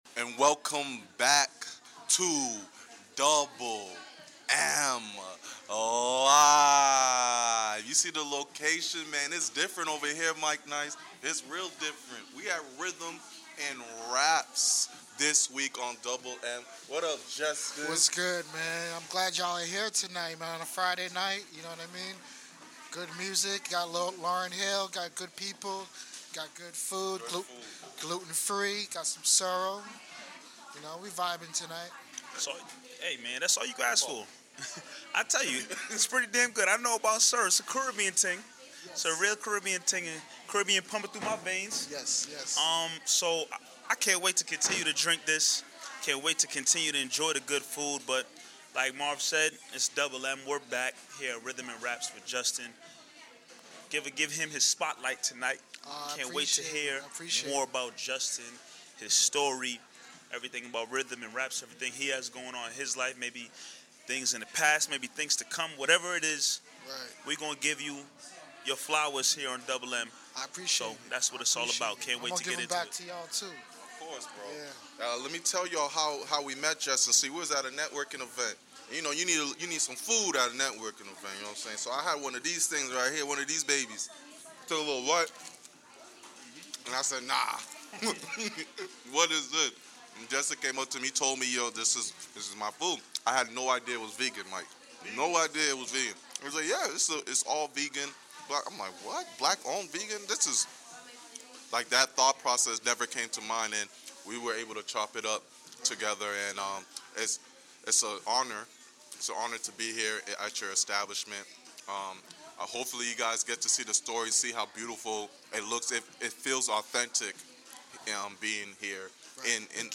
Join us for an intimate and insightful conversation that will leave you feeling motivated and uplifted.